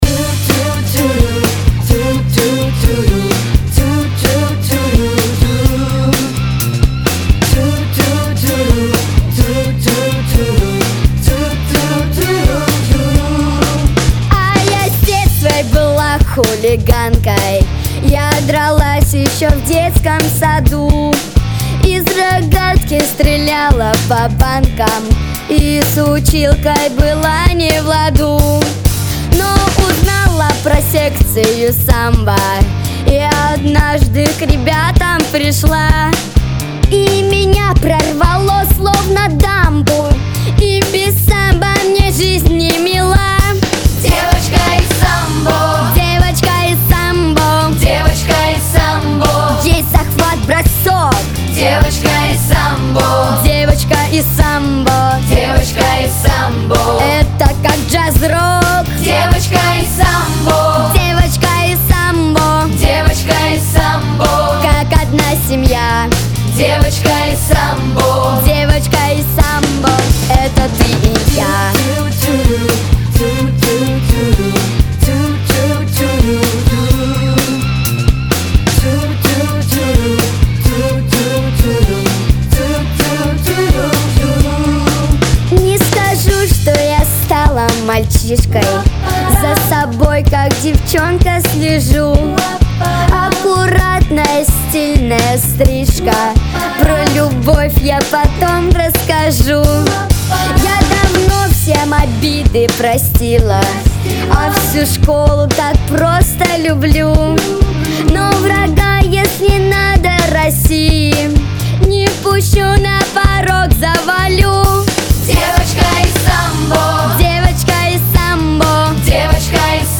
Аудиокнига Единоборства | Библиотека аудиокниг